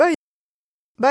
Moita Verde (nord)